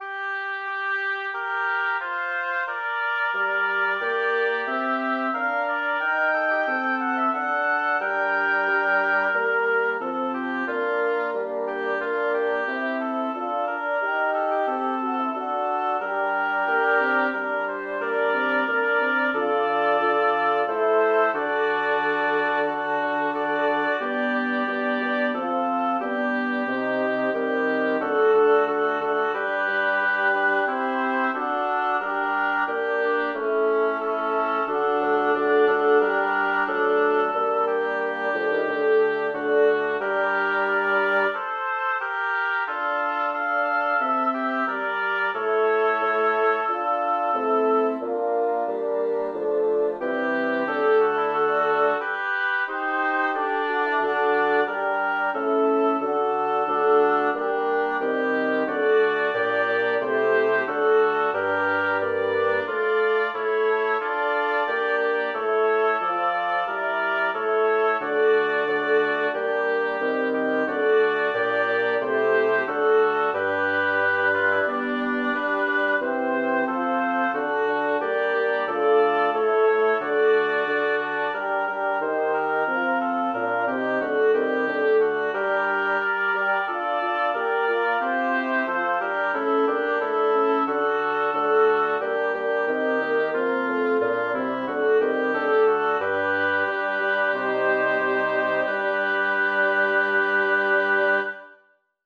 Title: Ist keiner hie der sprich zu mir Composer: Orlando di Lasso Lyricist: Number of voices: 5vv Voicing: SATTB, or SATBarB Genre: Secular, Lied
Language: German Instruments: A cappella